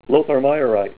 Help on Name Pronunciation: Name Pronunciation: Lotharmeyerite + Pronunciation
Say LOTHARMEYERITE